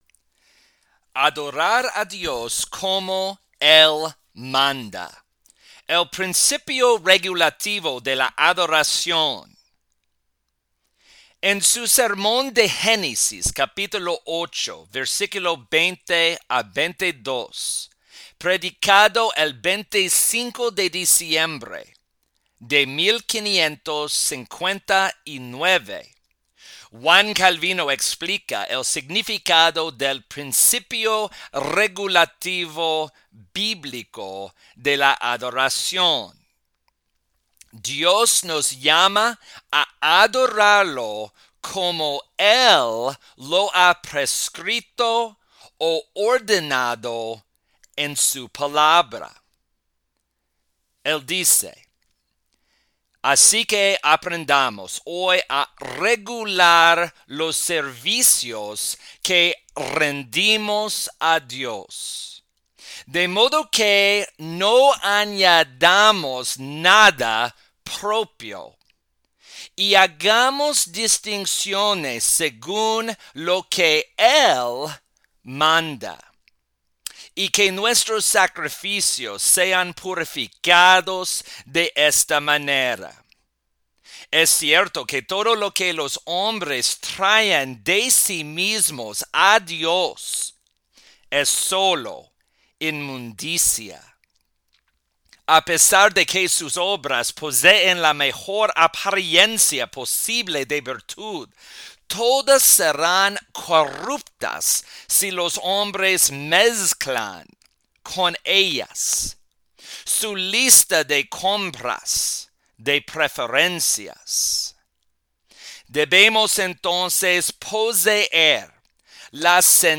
Sermones